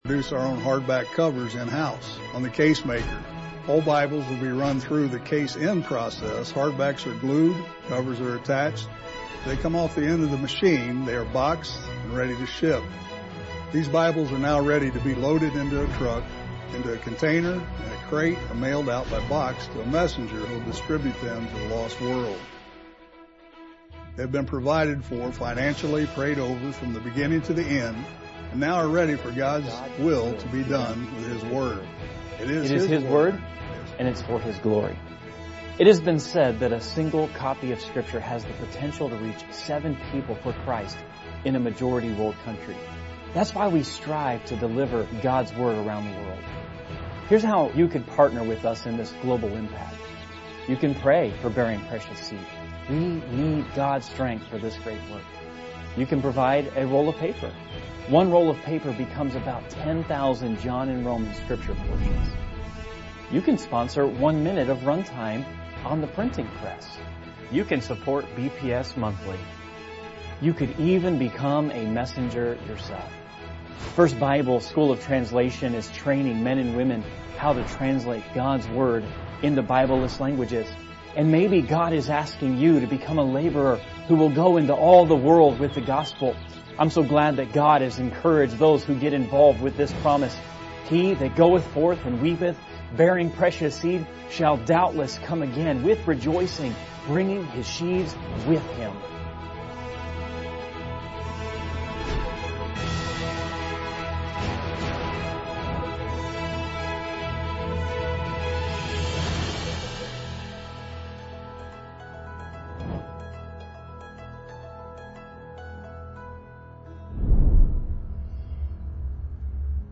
Sermons | Heartland Baptist Fellowship